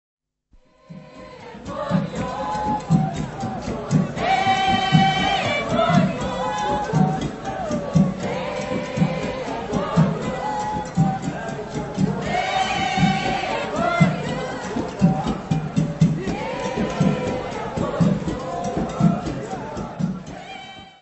Carnavals du Bresil : Rio, Recife, Bahia : live recording = Carnivals of Brazil
Área:  Tradições Nacionais
Ecole de Samba - Batucada - Ambiance - Rio.